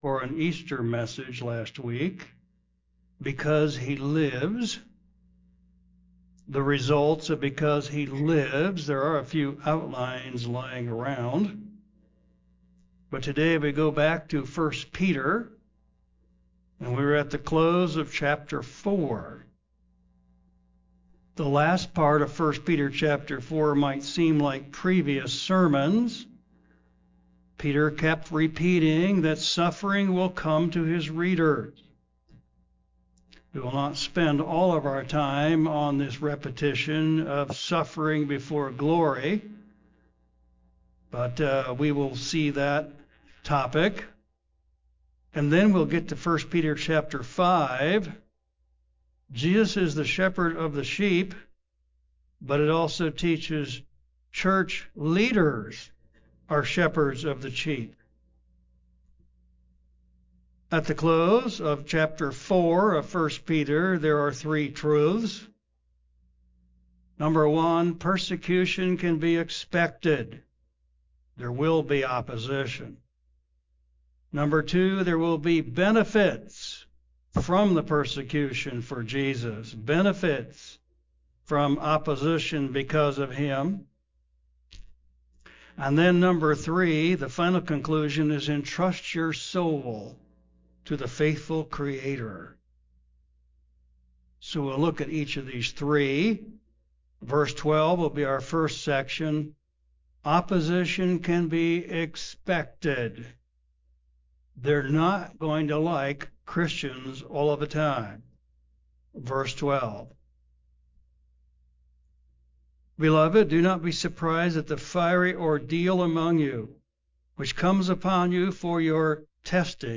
Latest audio sermon from Sunday, Apr 12th 2026